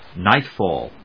音節níght・fàll 発音記号・読み方
/ˈnaɪˌtfɔl(米国英語), ˈnaɪˌtfɔ:l(英国英語)/